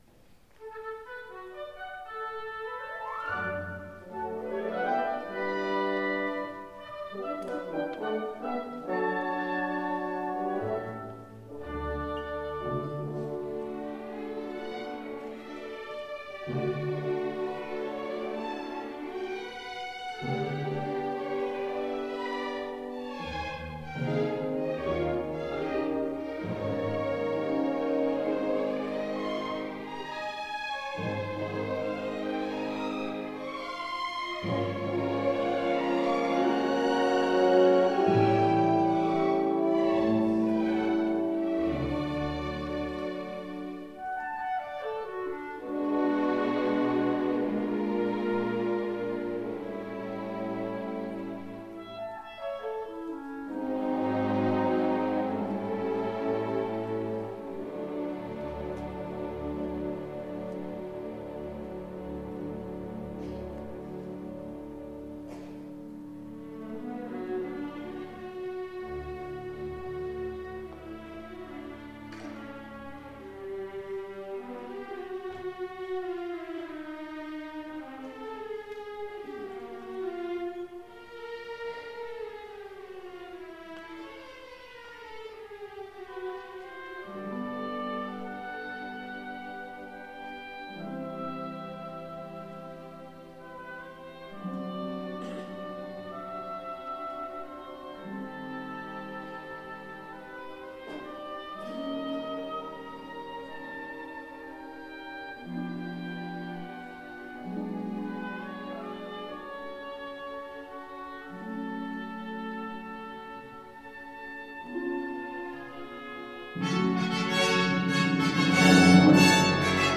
Orchestra
Style: Classical